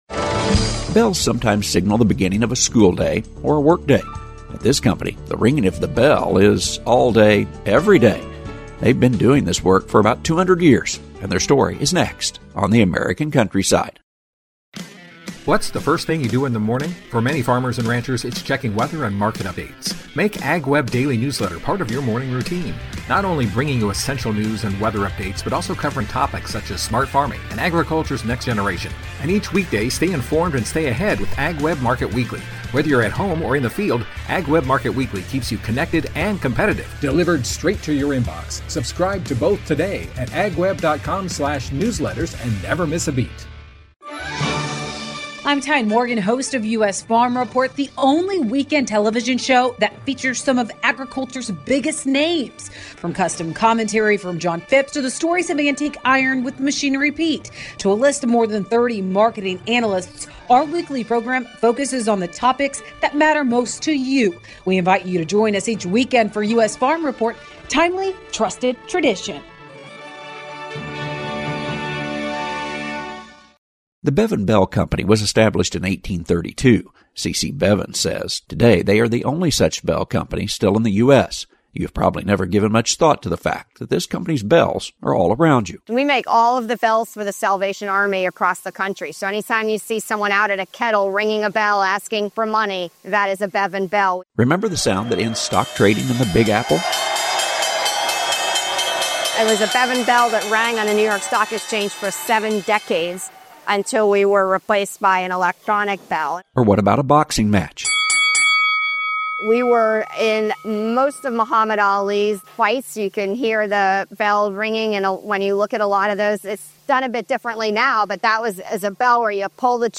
At this company, the ringing of the bell is all day, every day. They’ve been doing this work for about 200 years…